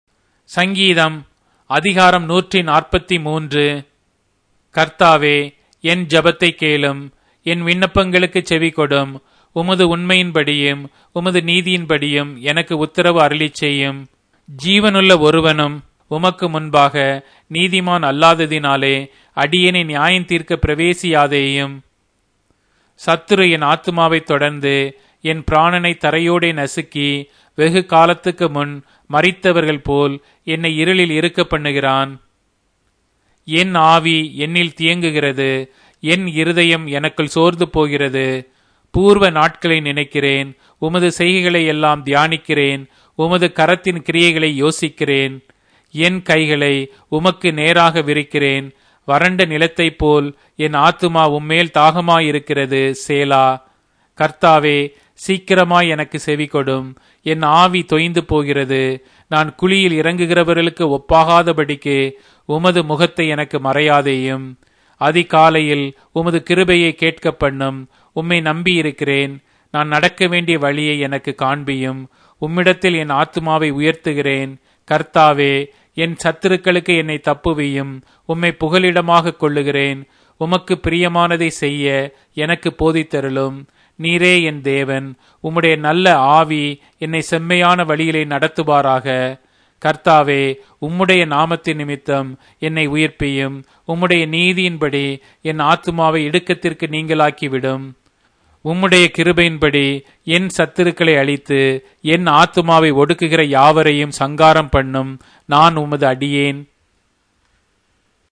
Tamil Audio Bible - Psalms 92 in Ervte bible version